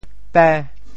潮州发音 潮州 bê5